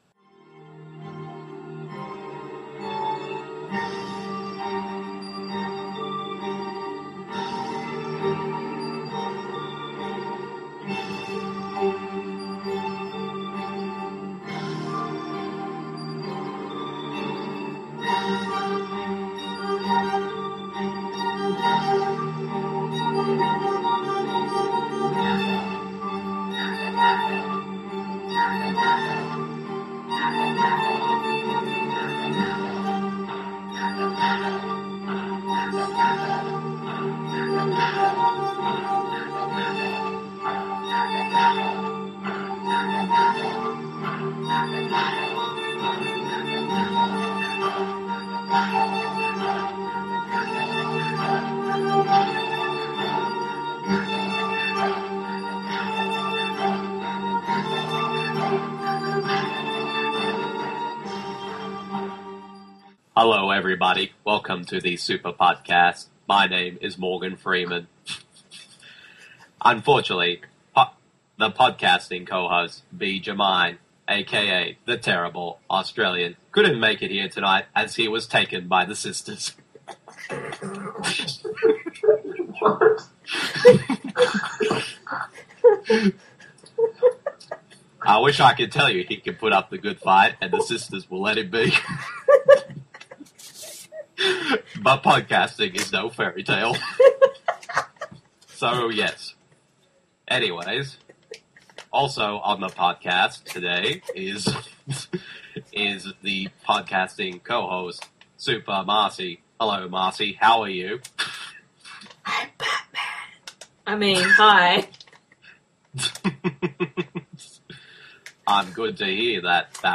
We have a special appearance by Morgan Freeman, you don’t want to miss that…